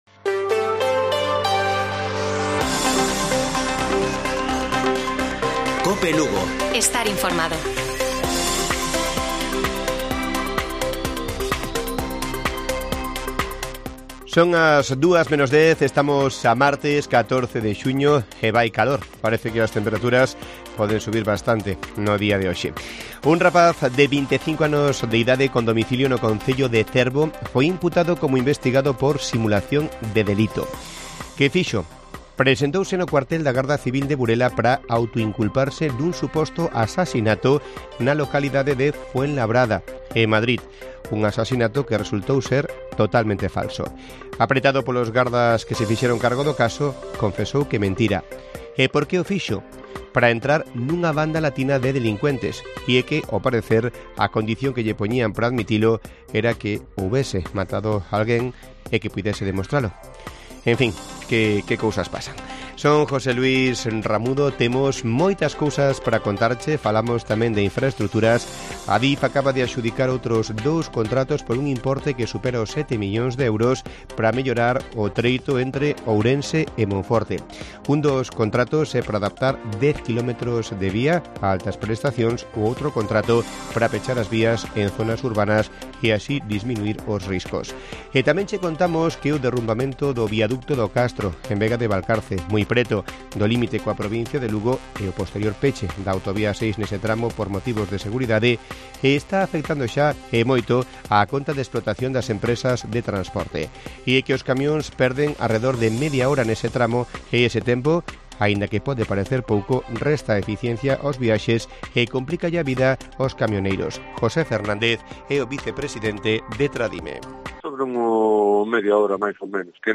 Informativo Mediodía de Cope Lugo. 14 DE JUNIO. 13:50 horas